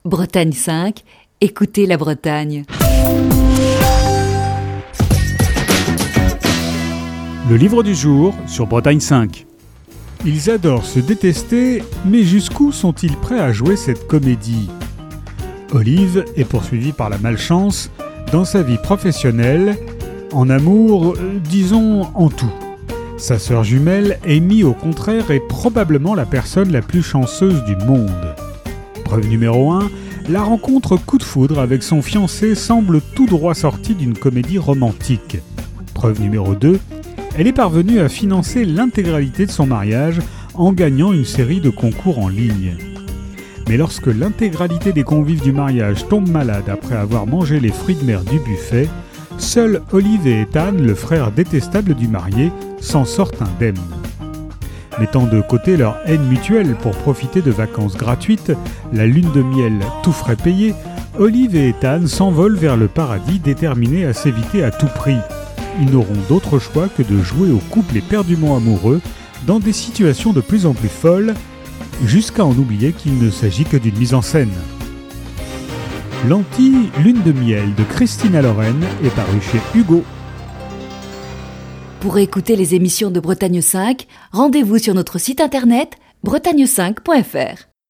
Chronique du 10 septembre 2020.